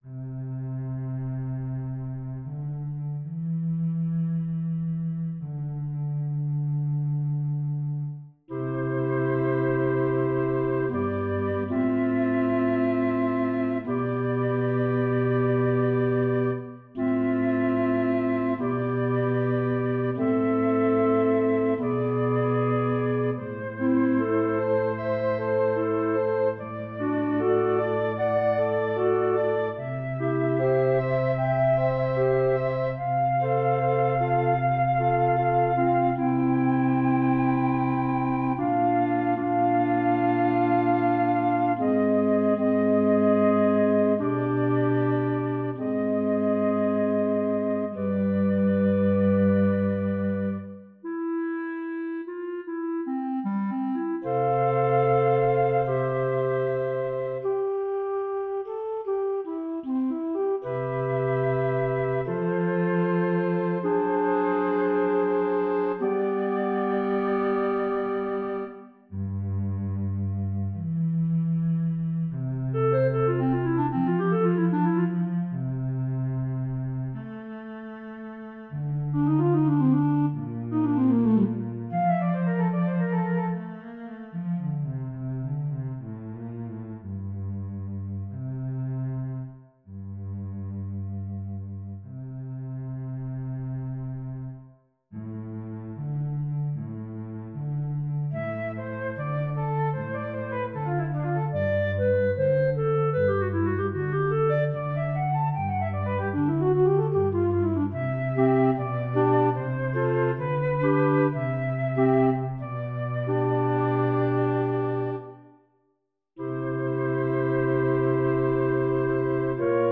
All the instrumental works are recorded through Finale: notation software that gives me the sound of virtually any instrument, from simple piano to a symphony orchestra, and astonishingly lifelike.
This music is my attempt to convey those feelings. Its serene moments are punctuated by active passages, as one might hear birds or other creatures settling as darkness falls.
Flute, alto flute, clarinet, and cello